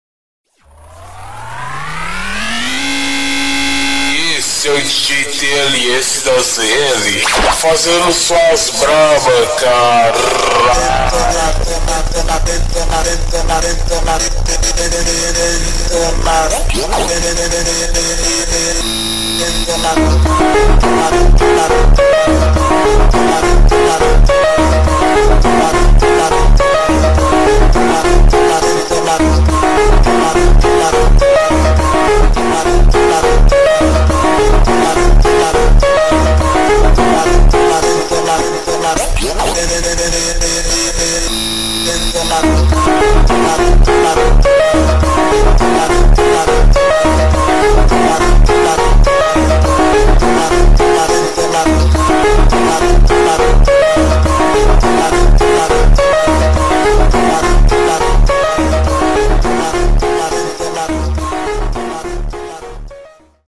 (slowed reverb)